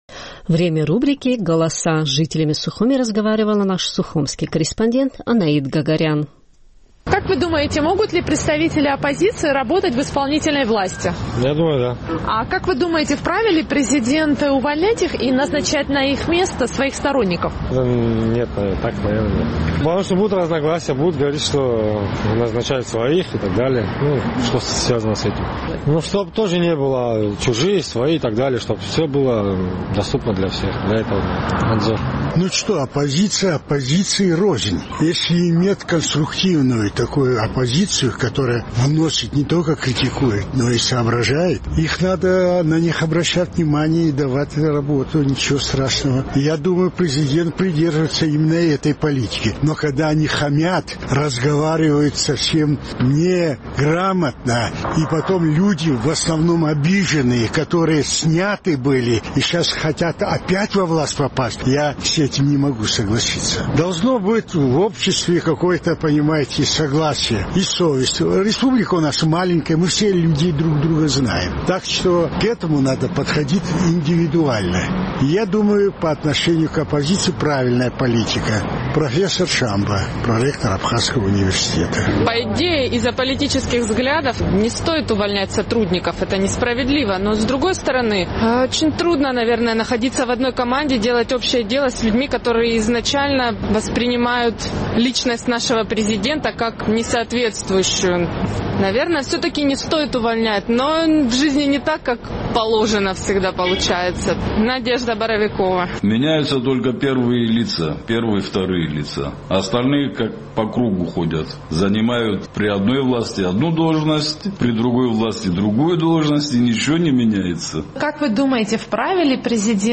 Наш сухумский корреспондент интересовалась мнением жителей абхазской столицы по поводу того, вправе ли президент увольнять представителей оппозиции и назначать на их места своих сторонников.